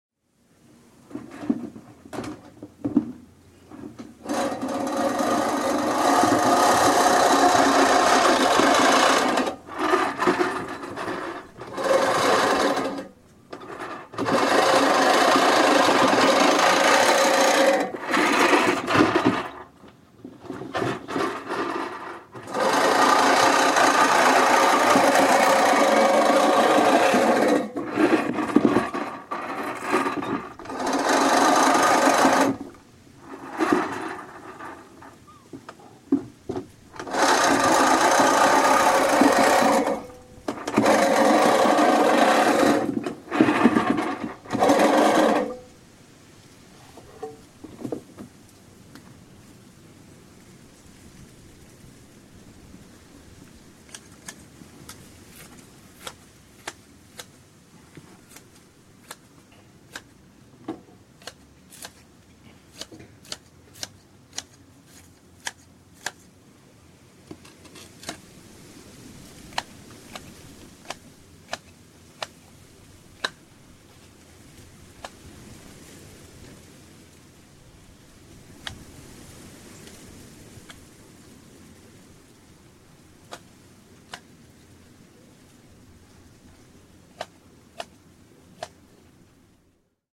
Giving the lawn a trim - a breezy day